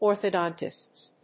/ˌɔrθʌˈdɑntʌsts(米国英語), ˌɔ:rθʌˈdɑ:ntʌsts(英国英語)/